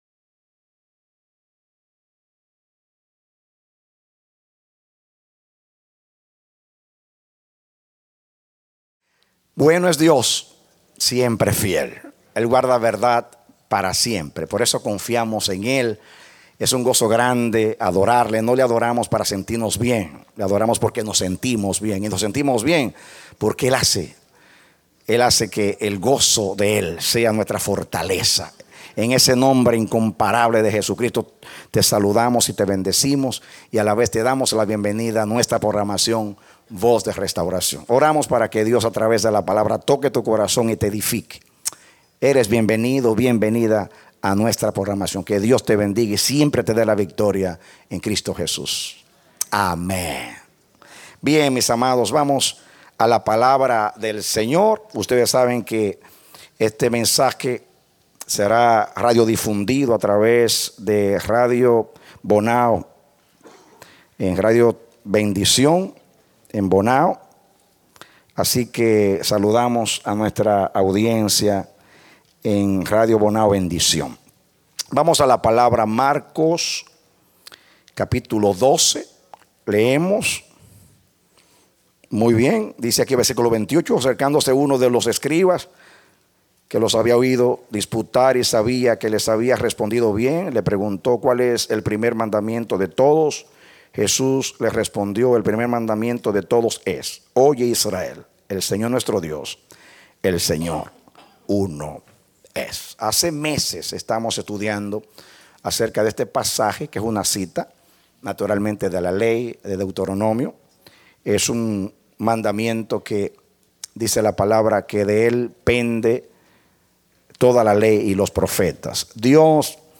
Mensaje: “Nuestro Dios Uno es # 26”
Serie Predicado Febrero 19, 2013